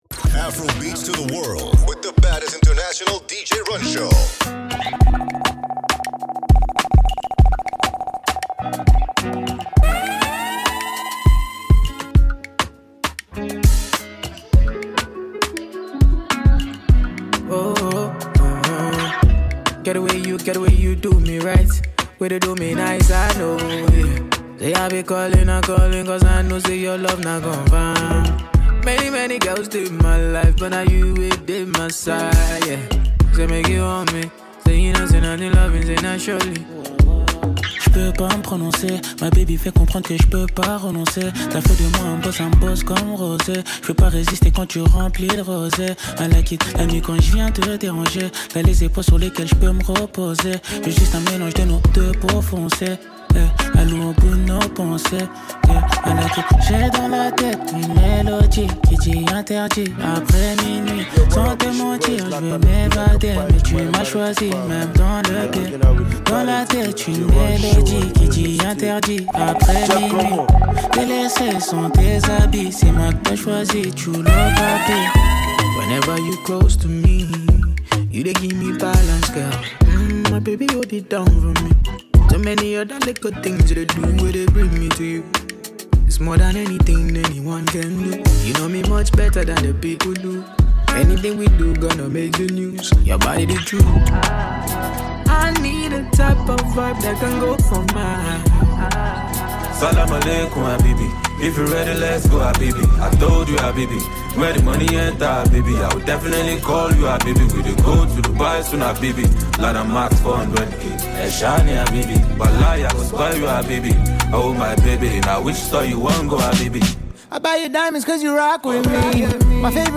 Afrobeats, amapiano, and Afro-fusion classics